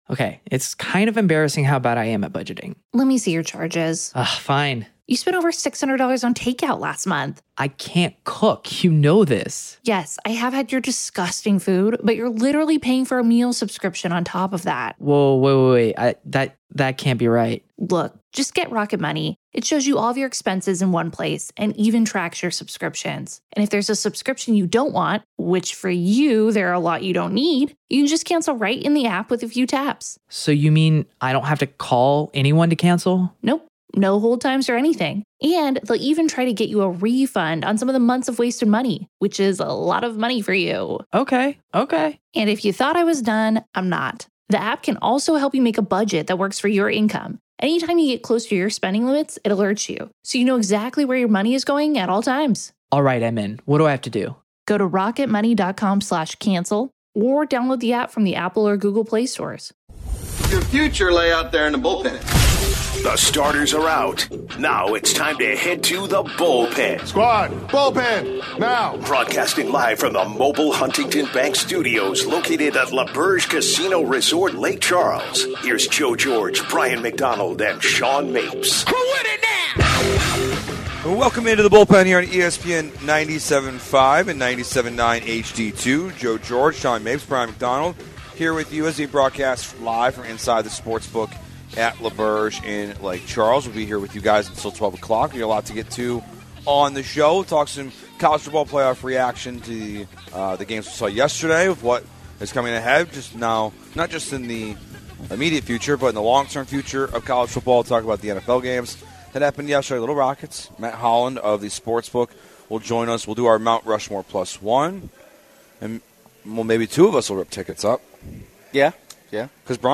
Live from L'auberge in Lake Charles